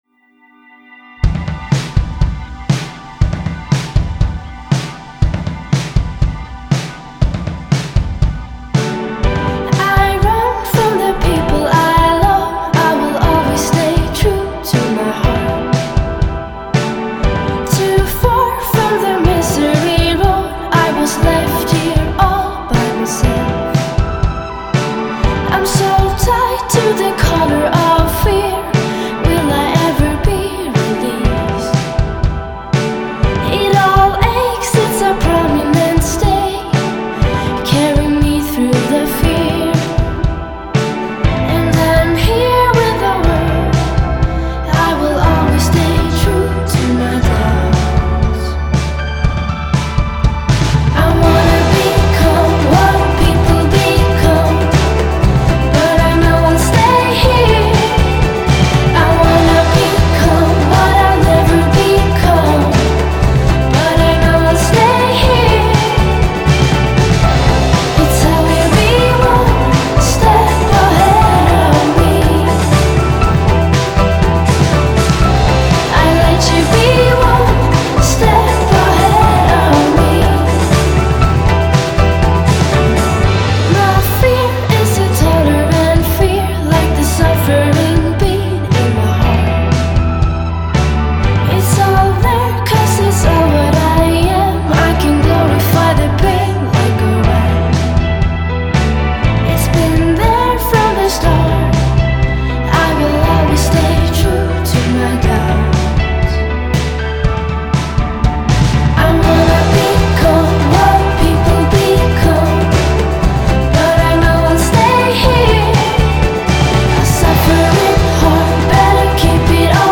Swedish singer and musician.
Genre: Indie, Pop